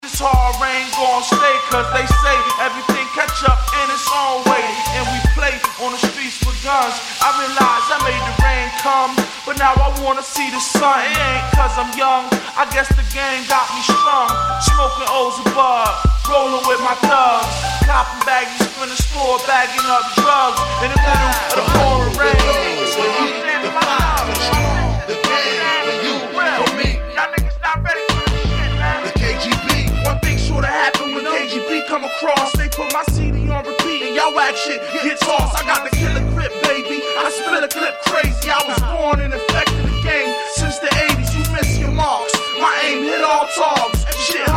east coast gangsta rap